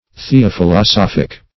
Search Result for " theophilosophic" : The Collaborative International Dictionary of English v.0.48: Theophilosophic \The`o*phil`o*soph"ic\, a. [Gr.